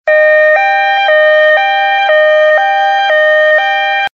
>Added police car >Added ambulance siren >Added smoke trail to rockets.
ambulance.ogg